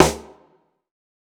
TC3Snare5.wav